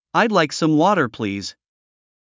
アメリカ英語：I’d like some water, please.
アメリカ英語の例文音声では”water”の”t”の部分が”d”で発音されています。
AE-water2.mp3